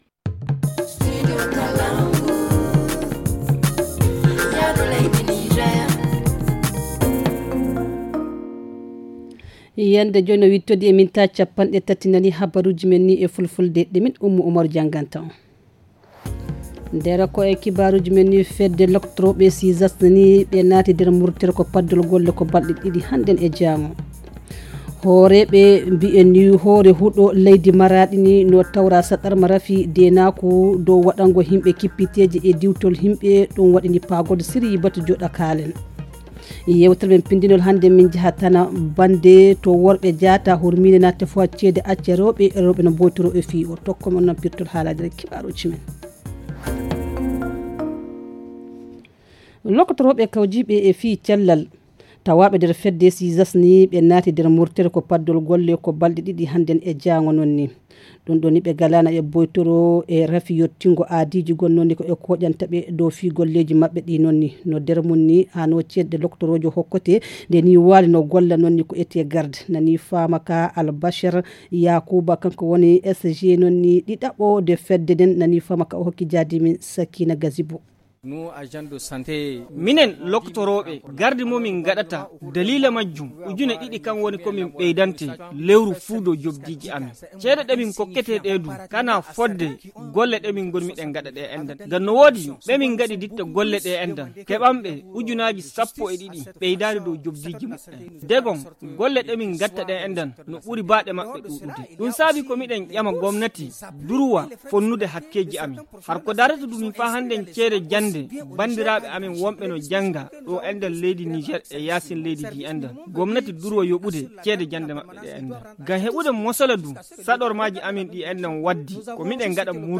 Le journal du 13 septembre 2022 - Studio Kalangou - Au rythme du Niger